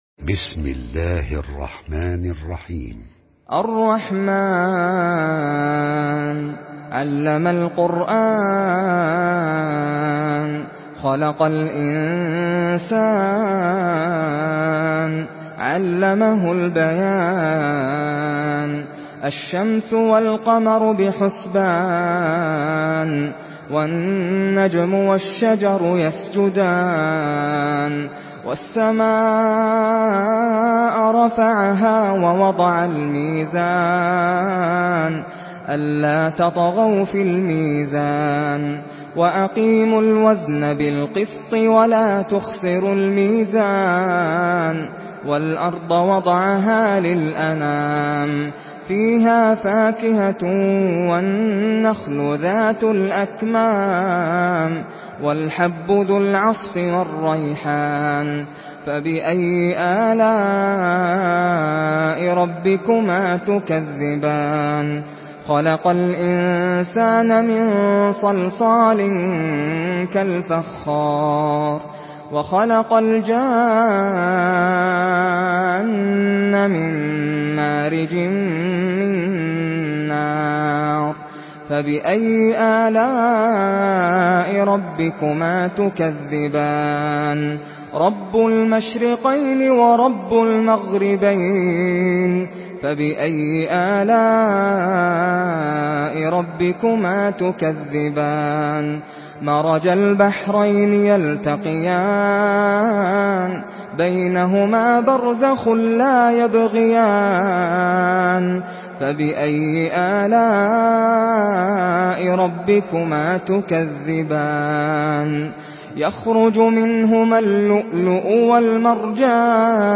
Surah Rahman MP3 by Nasser Al Qatami in Hafs An Asim narration.
Murattal Hafs An Asim